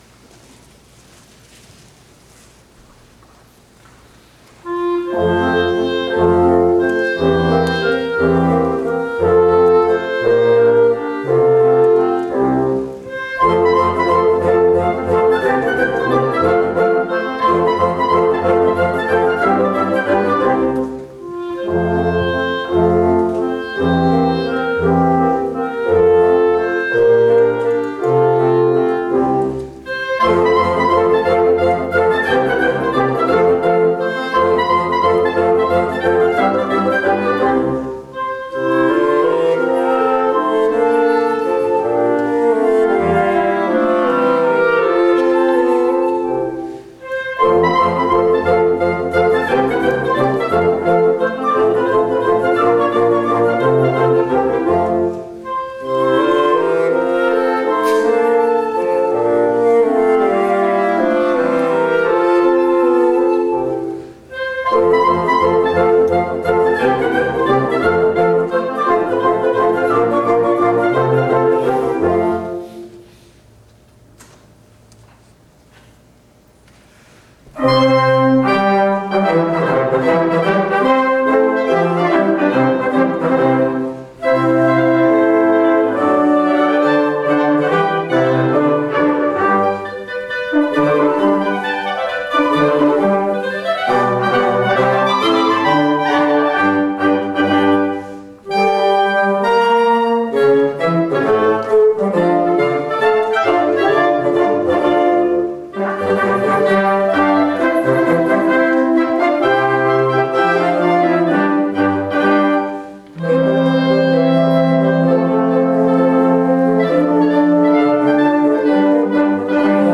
On Sunday, September 9, 2018, the Lititz Moravian Collegium Musicum presented an inaugural concert in front of a full house.
Flutes, Clarinets, Bassoon, Serpent, Trumpet, Horns